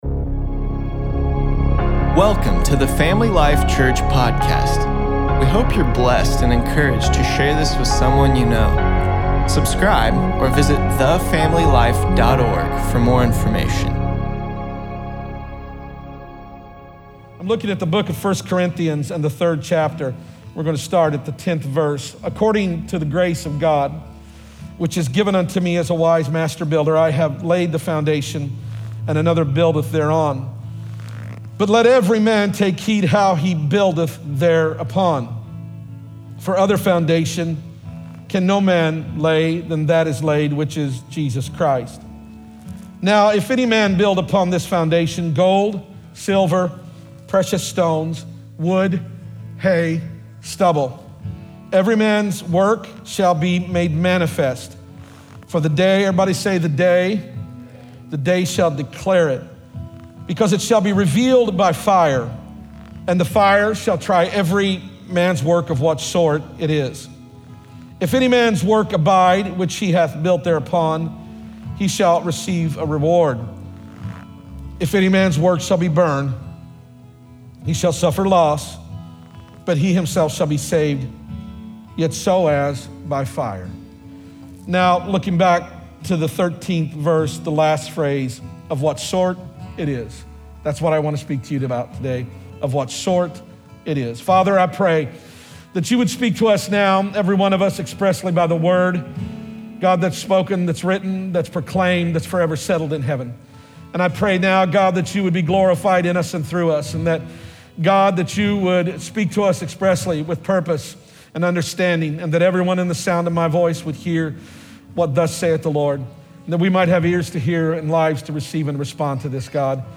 8.2.20_Sermon_p.mp3